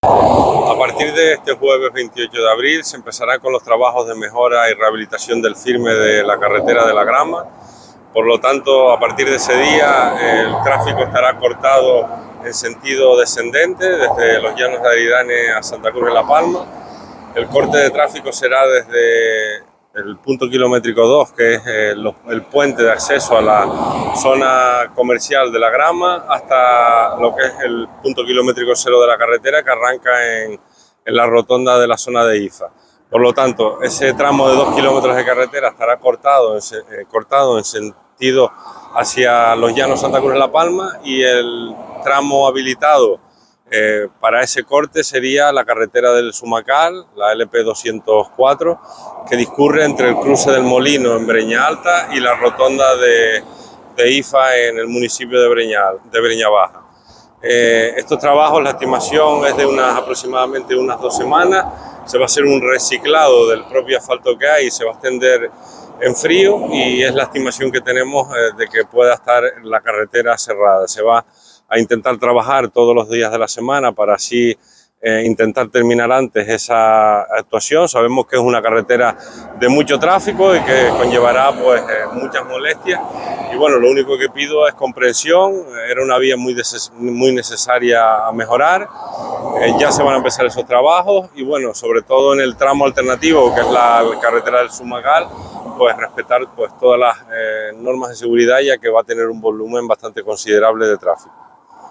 Así lo ha confirmado el vicepresidente del Cabildo y consejero de Infraestructuras, Borja Perdomo, quien ha indicado que se habilitará como desvío alternativo la carretera LP-204 El Zumacal – San Antonio, es decir, entre la rotonda de El Molino y la glorieta de IFA.
Declaraciones Borja Perdomo audio_0.mp3